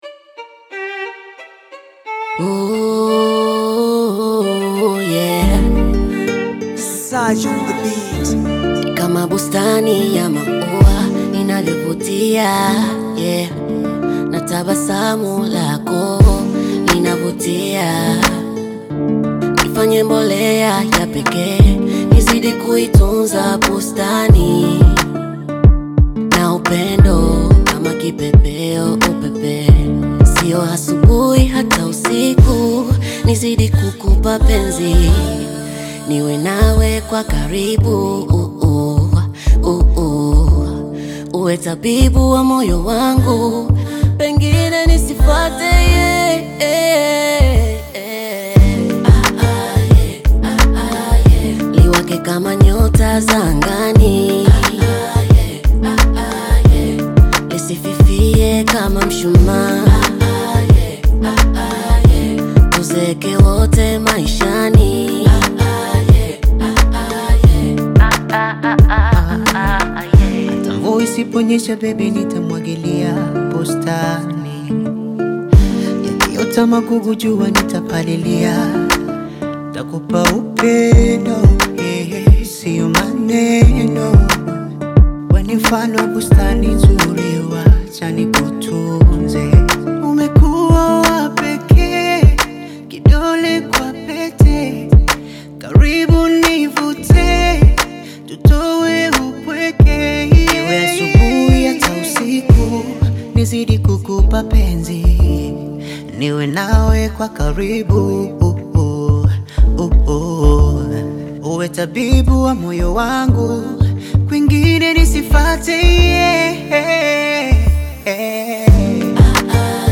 Tanzanian Bongo Flava
Bongo Flava song